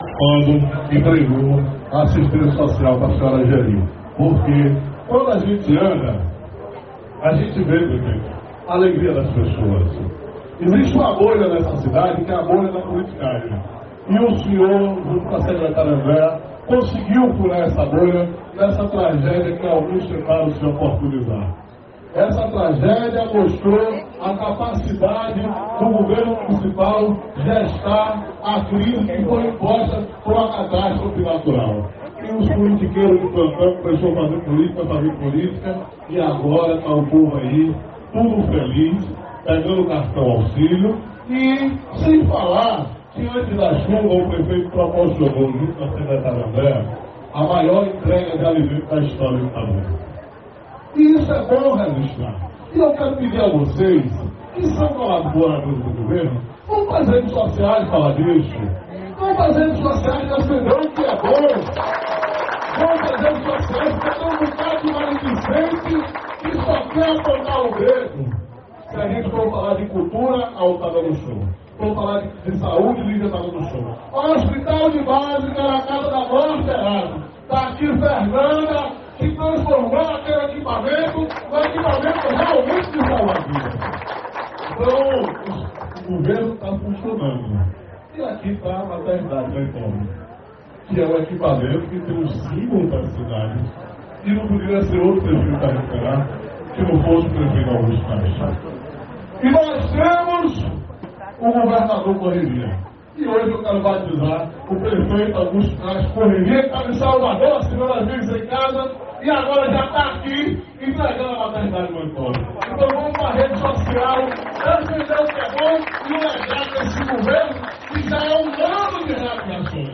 A afirmação foi feita durante discurso na reinauguração da maternidade Otaciana Pinto, antiga Mãe Pobre, nesta quinta-feira (27).